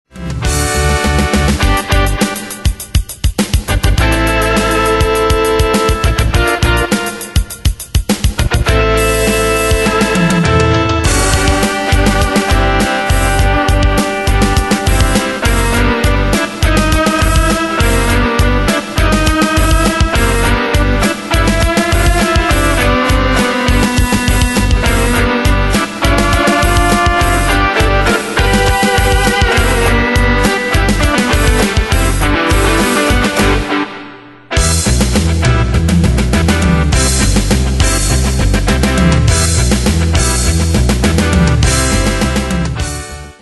Style: Rock Année/Year: 1970 Tempo: 102 Durée/Time: 3.47
Danse/Dance: Rock Cat Id.
Pro Backing Tracks